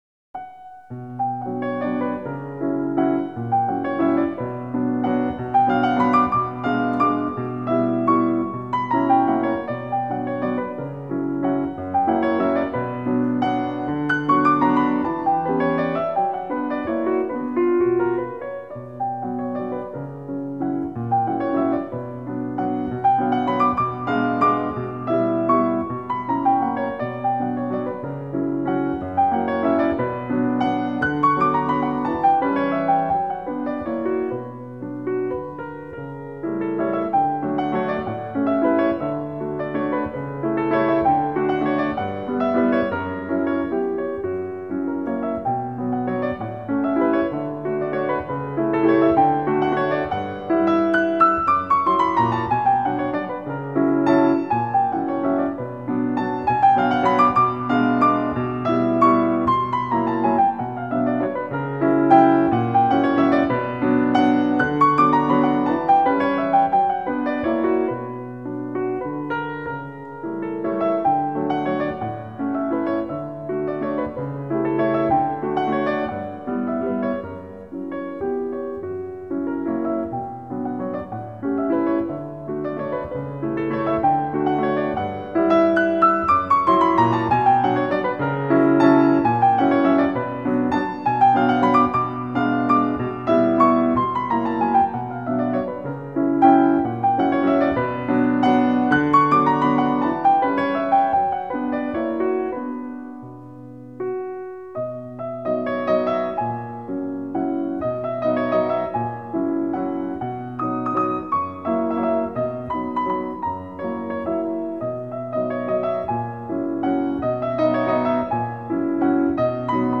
Шопен - Вальс №10 си минор, соч.69 №2.mp3